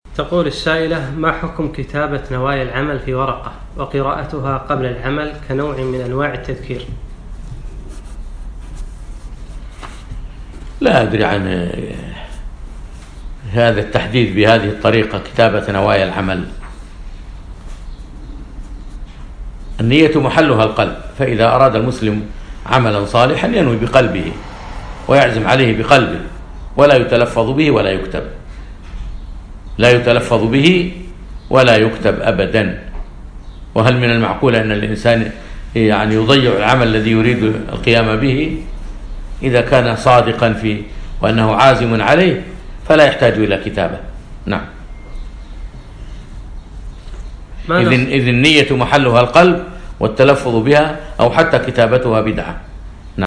يوم الأحد 5 رجب 1438 الموافق 2 4 2017 في أترجة الفحيحيل نسائي صباحي